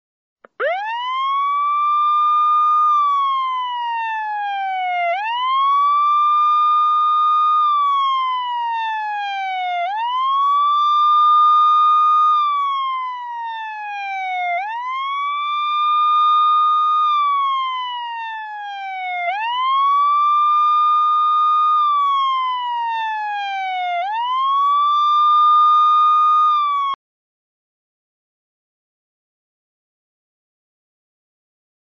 signalka.mp3